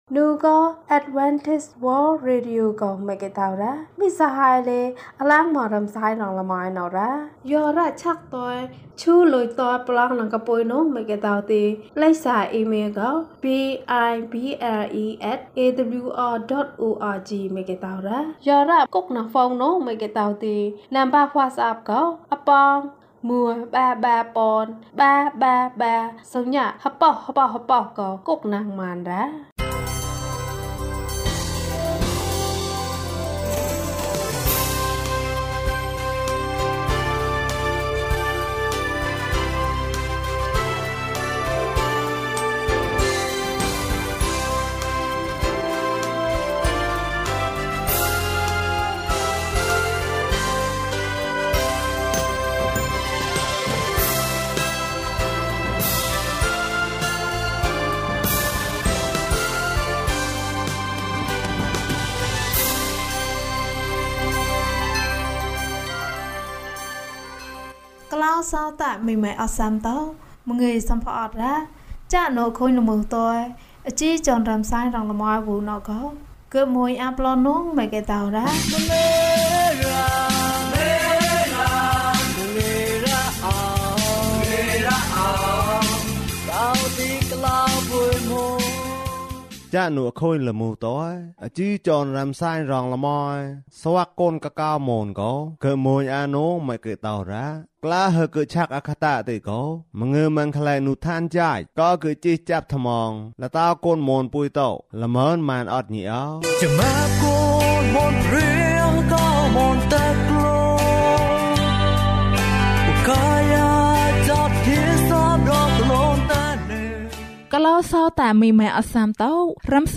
ခရစ်တော်ထံသို့ ခြေလှမ်း။၂၉ ကျန်းမာခြင်းအကြောင်းအရာ။ ဓမ္မသီချင်း။ တရားဒေသနာ။